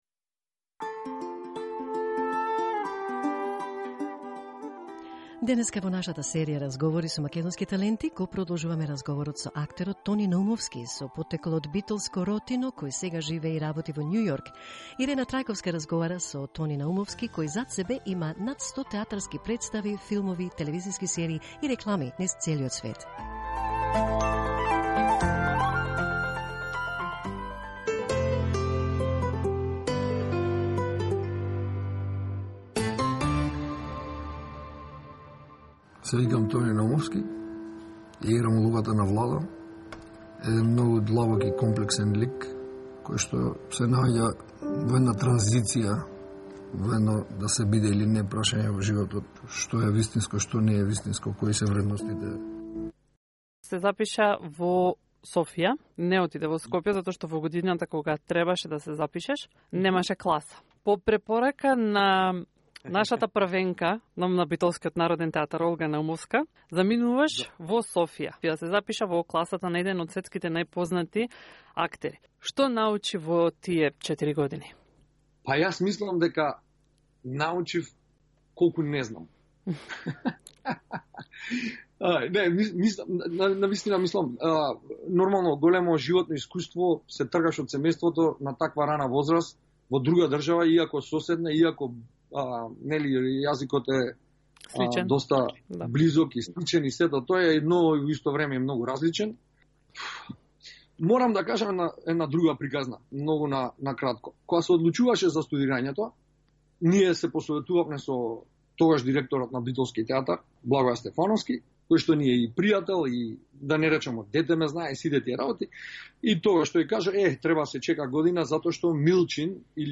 In the second part of the interview with the international acting talent